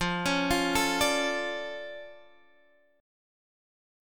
F Augmented 6th